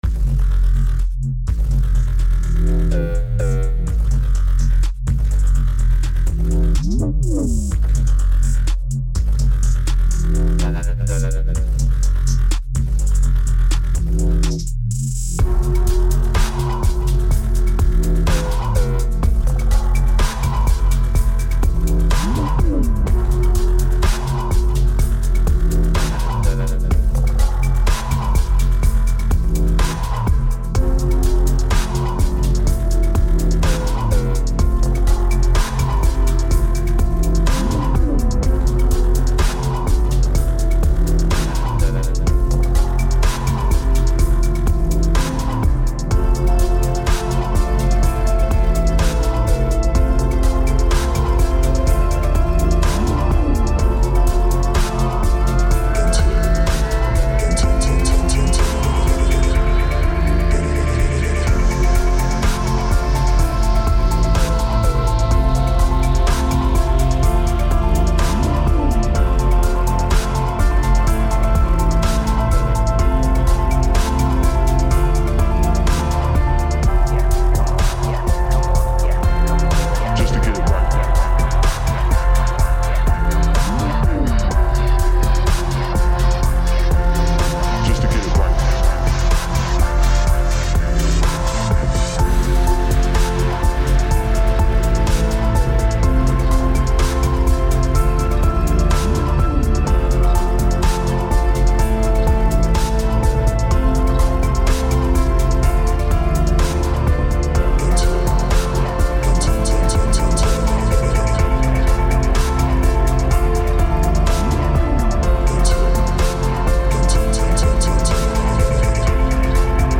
• Version Remix
It makes it a much more bassy and robust track.
Tempo 125BPM (Allegro)
Genre Slow Dubstep/Trap/Cinematic cross
Type Vocal Music
Mood Conflicting (Energetic/Aggressive/Chilled)